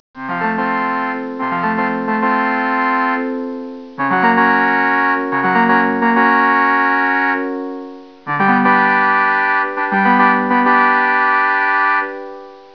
organ.wav